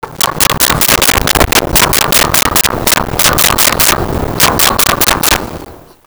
Chickens In Barn 02
Chickens in Barn 02.wav